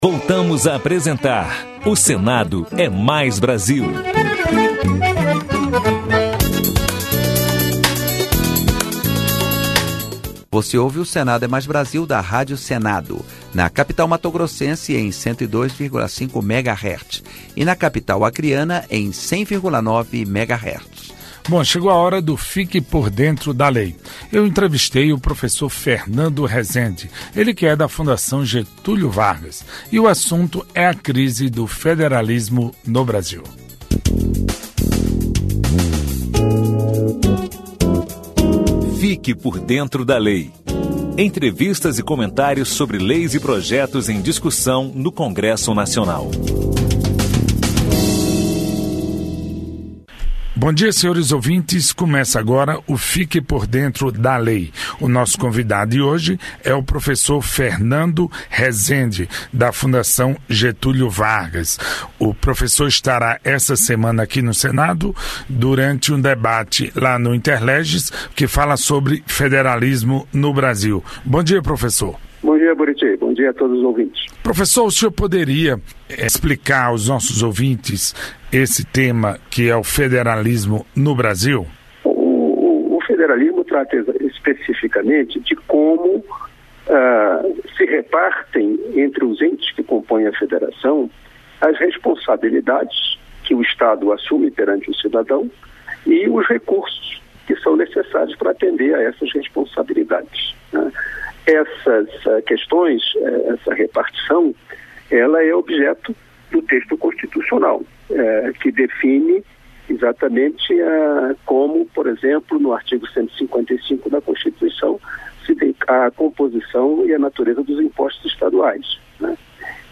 O Senado é Mais Brasil - 3º bloco Fique Por Dentro da Lei: Federalismo no Brasil. Entrevista com o professor da Fundação Getúlio Vargas